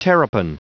Prononciation du mot terrapin en anglais (fichier audio)
Vous êtes ici : Cours d'anglais > Outils | Audio/Vidéo > Lire un mot à haute voix > Lire le mot terrapin